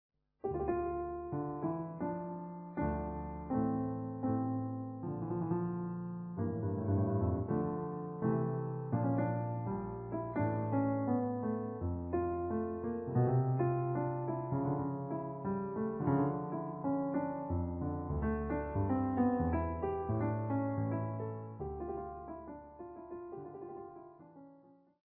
Les 4 Livres de Clavecin .
Je les ai enregistrés sur mon clavier numérique Roland RD700 (ce ne sont pas des fichiers midi).